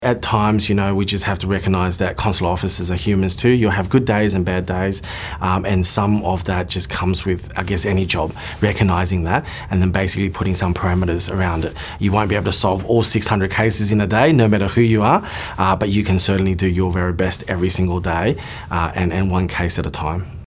a consular officer